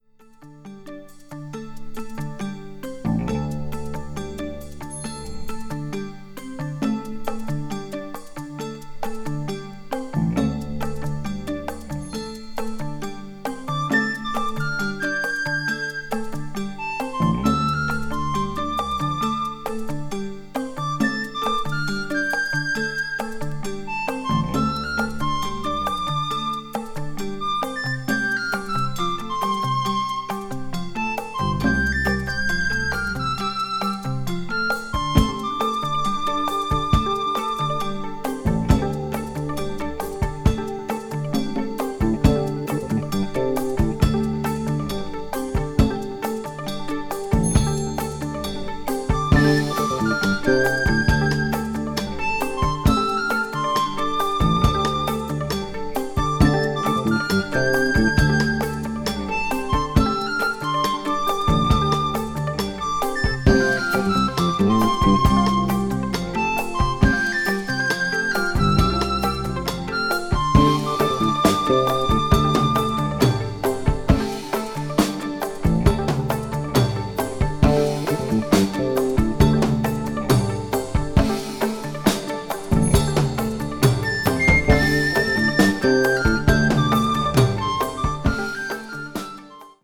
crossover   jazz rock   new age   progressive rock